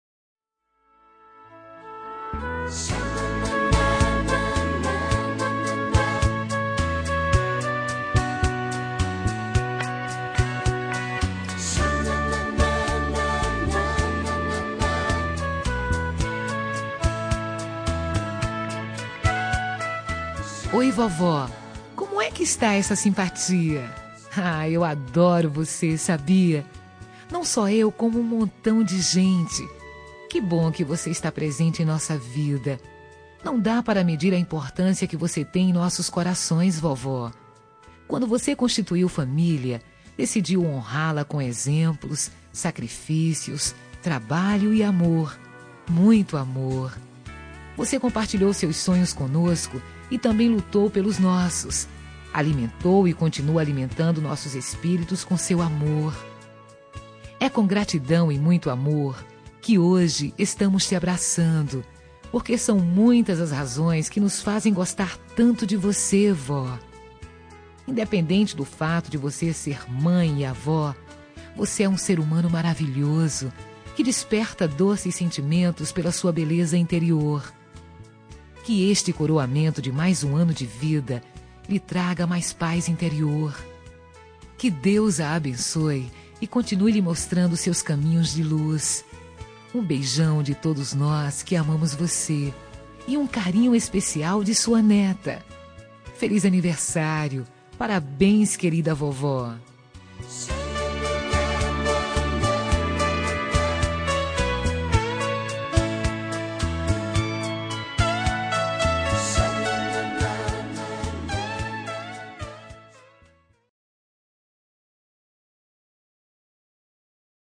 Telemensagem Aniversário de Avó – Voz Feminina – Cód: 2056